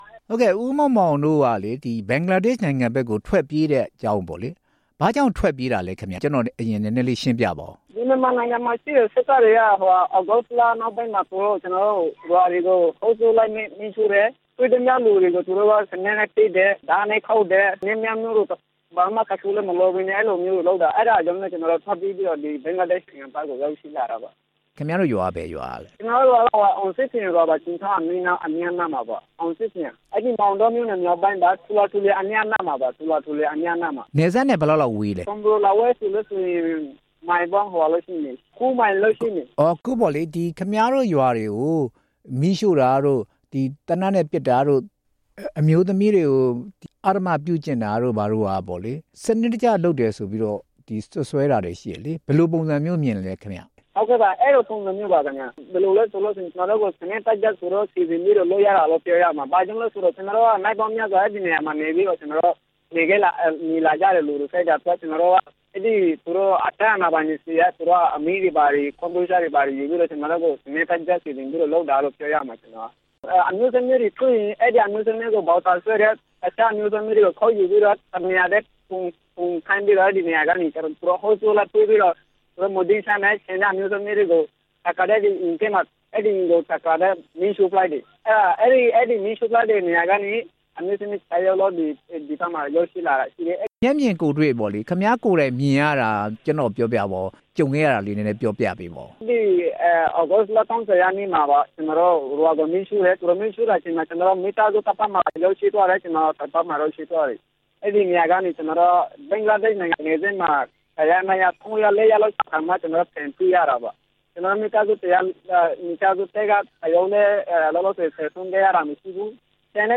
ကုတုပါလောင်စခန်းက ဒုက္ခသည်တစ်ဦးနဲ့ ဆက်သွယ်မေးမြန်းချက်
မေးမြန်းခန်း